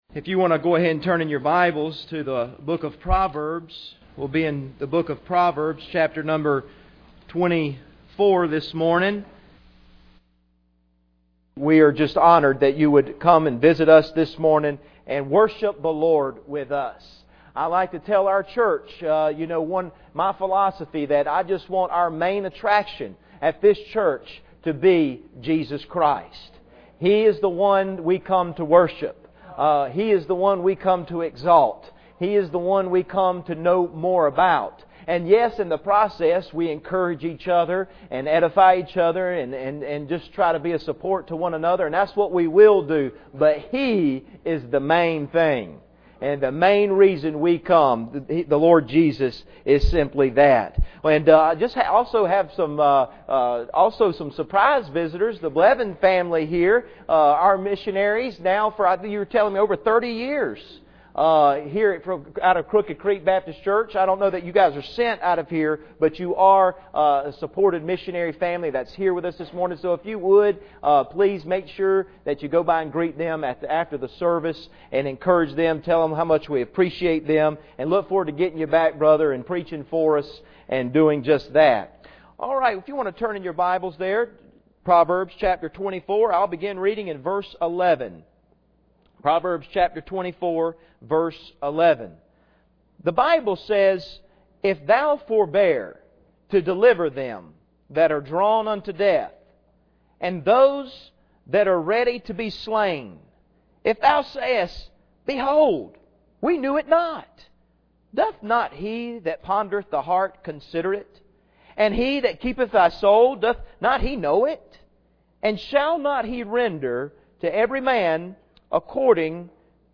Proverbs 24:11-12 Service Type: Sunday Morning Bible Text